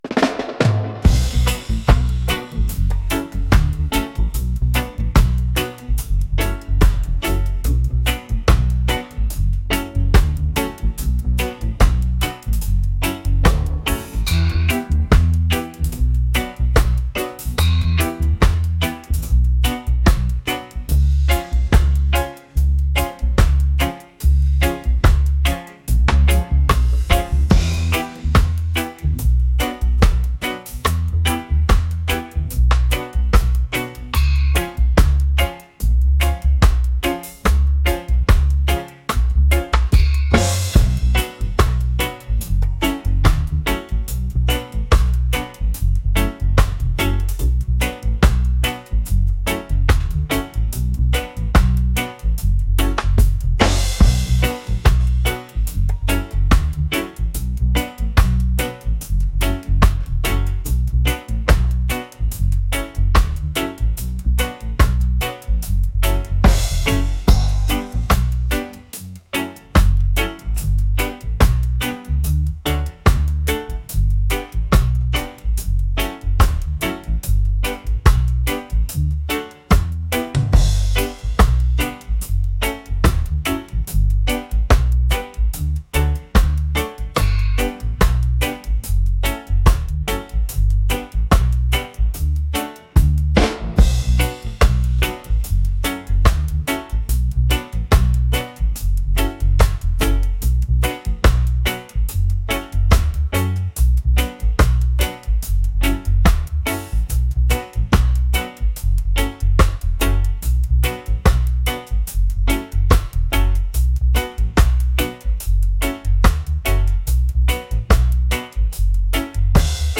reggae | laid-back